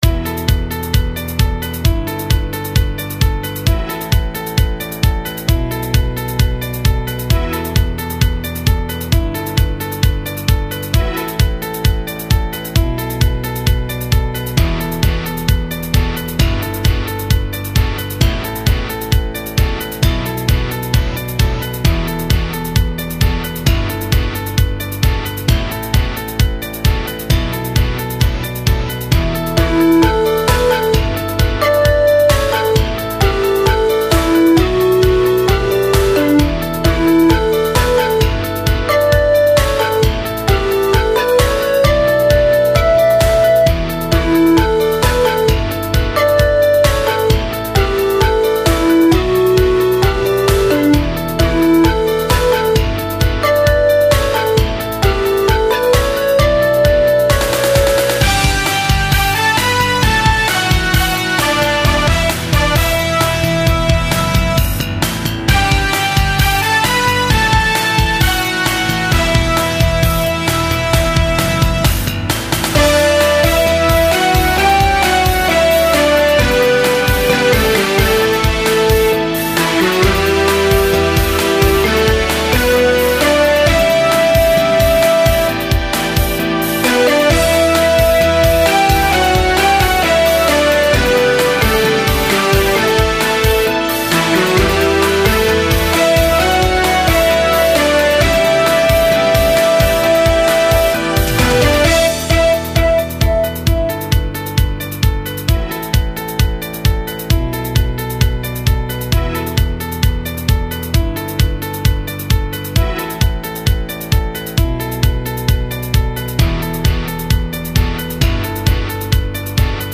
コーラスのセクションをもうちょっと引き伸ばして盛り上げたほうが、内容的に充実しそうだなと思ったので、メロディを書き加えた。
さっきのだと、ループの終わりとしても終わった感じがしない気がしたので、これだと結構一周した感じも出ていいんじゃないかと思う。